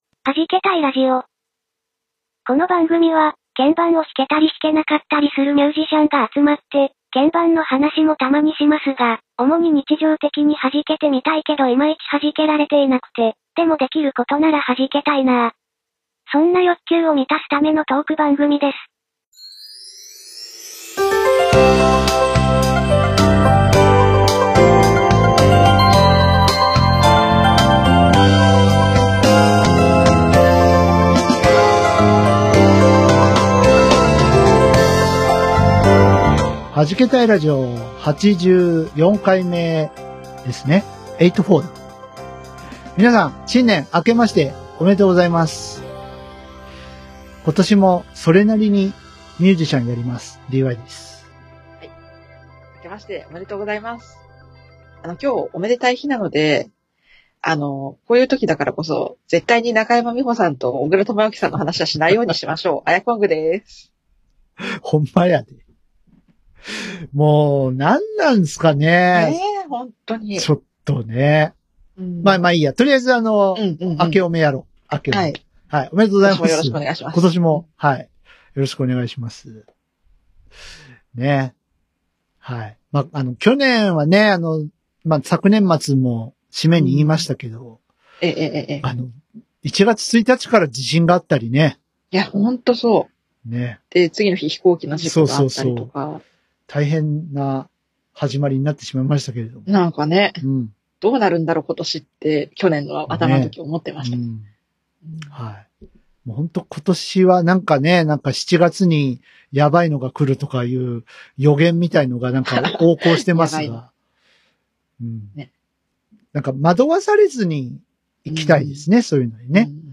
鍵盤を弾けたり弾けなかったりする３人のミュージシャンが、日常生活で弾けられないけど弾けたい、そんな欲求を満たすトーク番組。